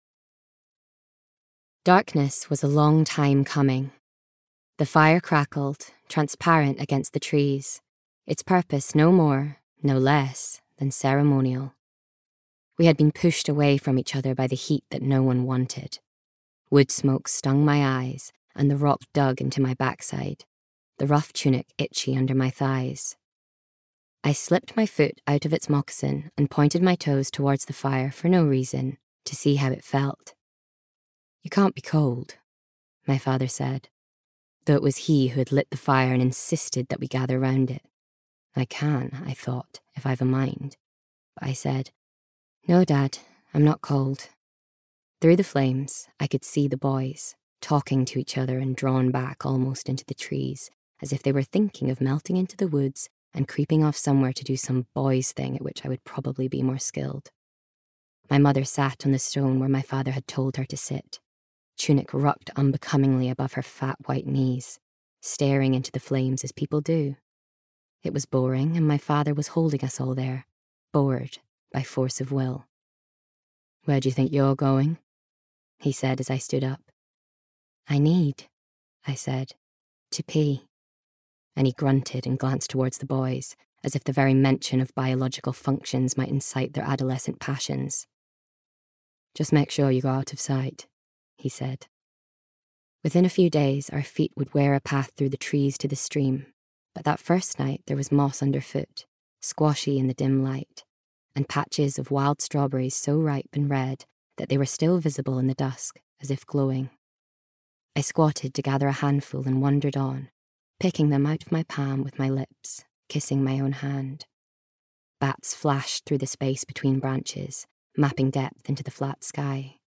Voice Reel
Audiobook 2 - Mulitple voices